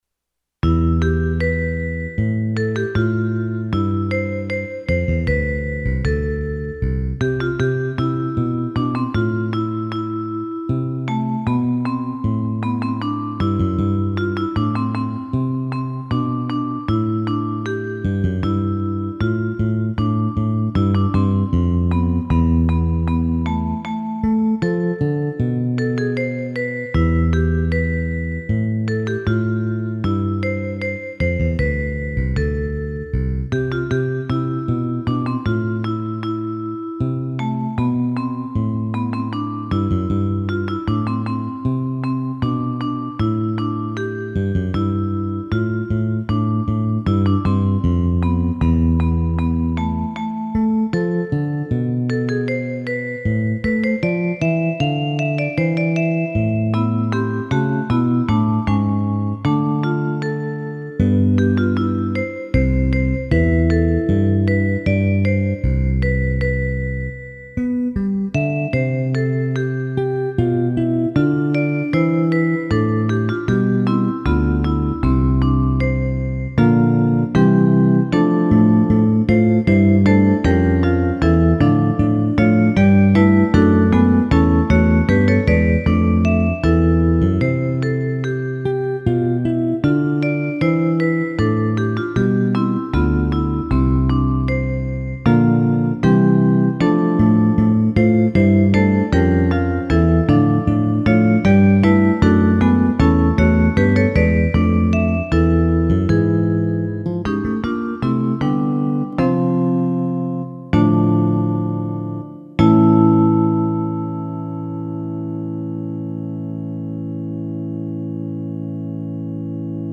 CHANSON ; POLYPHONIC MUSIC